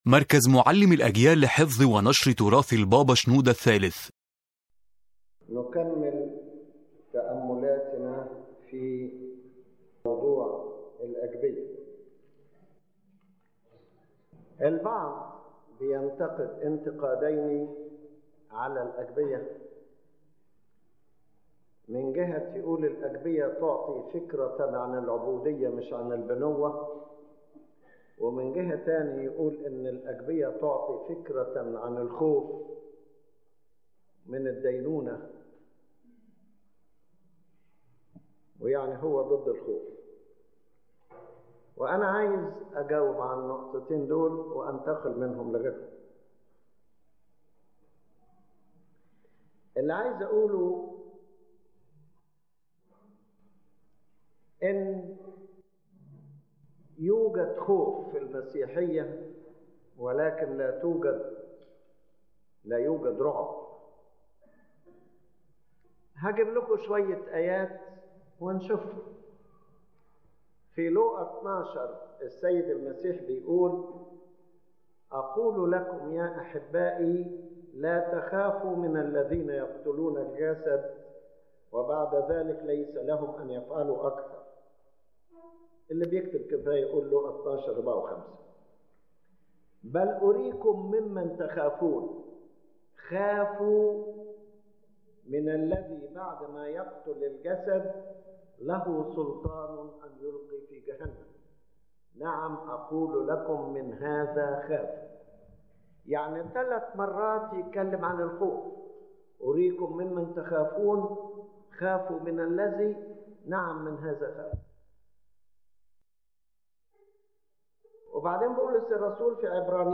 The lecture addresses the correct understanding of fear in spiritual life and responds to criticisms that the Agpeya focuses on servitude and fear instead of sonship and love.